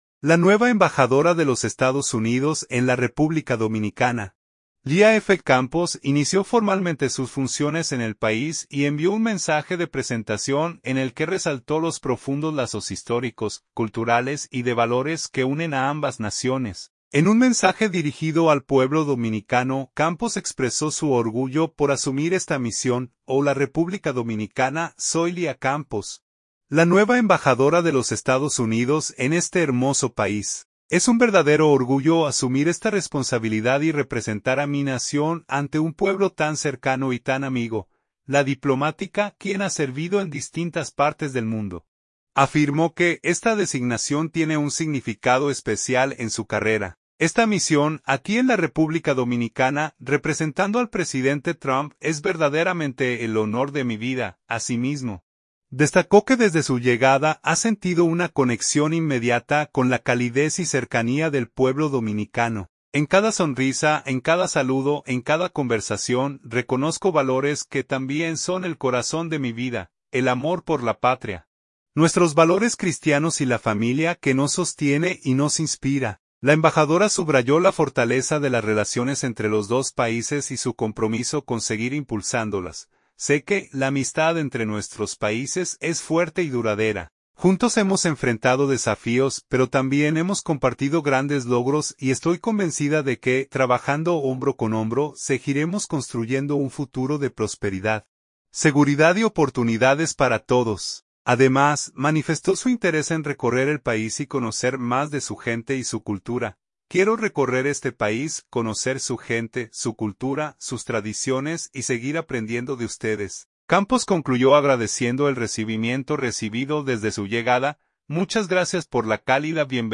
SANTO DOMINGO. – La nueva embajadora de los Estados Unidos en la República Dominicana, Leah F. Campos inició formalmente sus funciones en el país y envió un mensaje de presentación en el que resaltó los profundos lazos históricos, culturales y de valores que unen a ambas naciones.